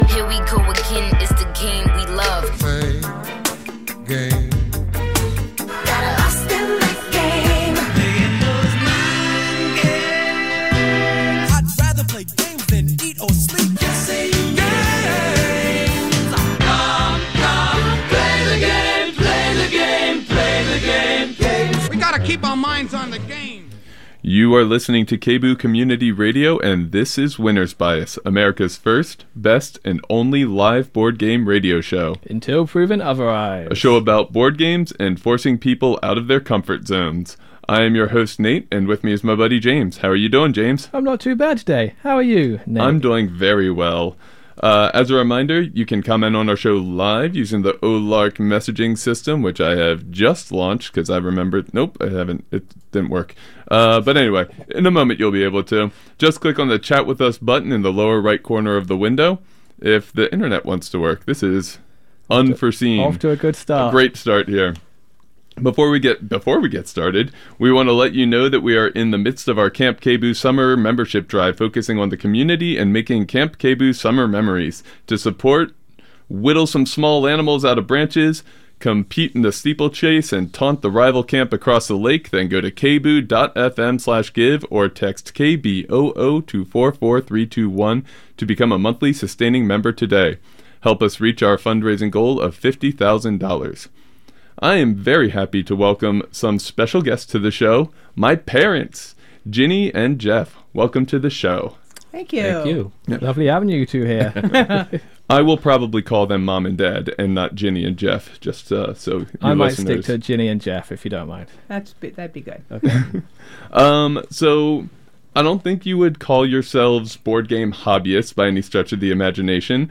Winner's Bias airs on KBOO on the 1st and 3rd Tuesdays of the month at 11 a.m.